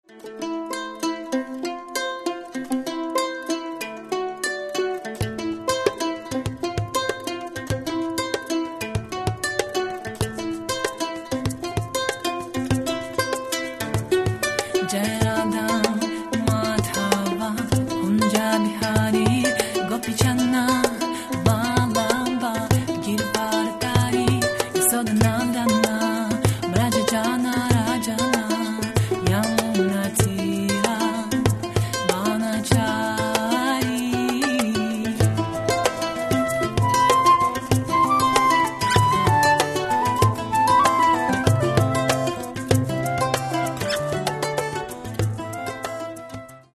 Каталог -> Рок и альтернатива -> Лирический андеграунд